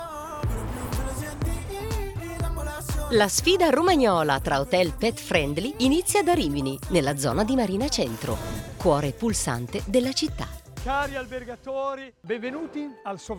nella narrazione del docu-reality televisivo